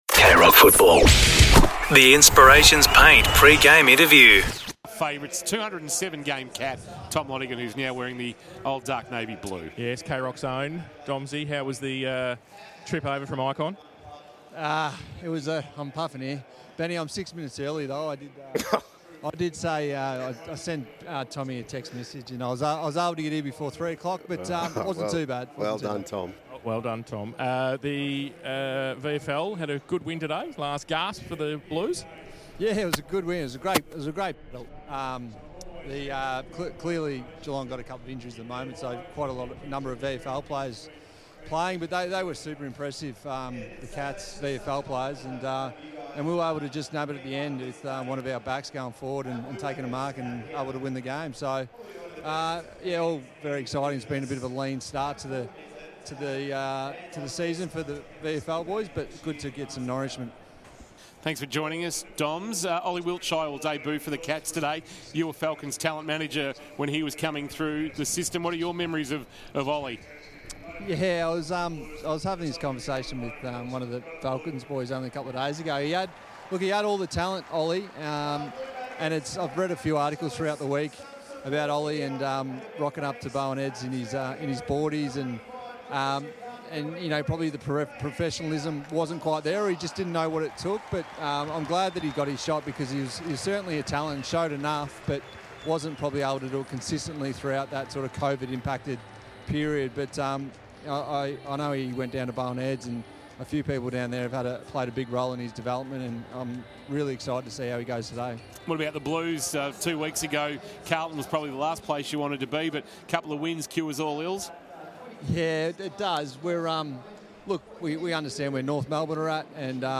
2025 - AFL - Round 7 - Carlton vs. Geelong: Pre-match interview